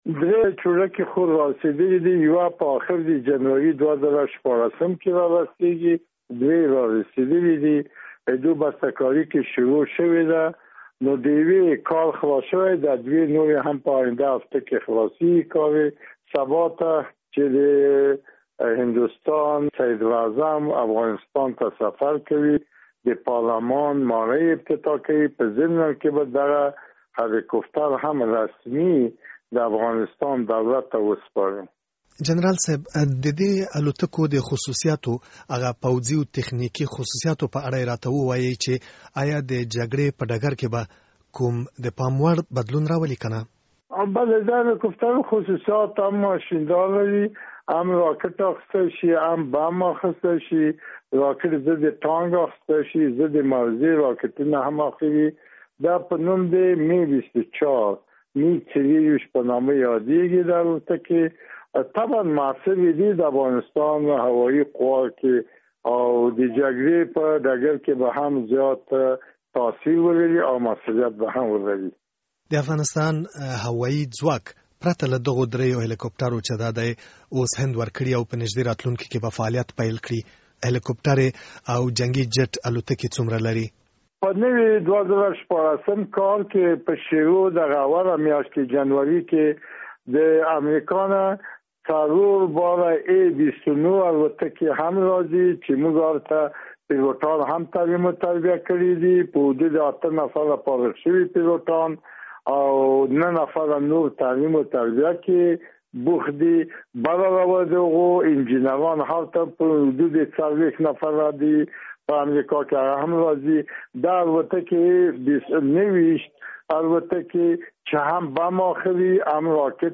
مرکه
له جنرال وهاب سره مرکه